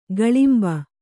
♪ gaḷimba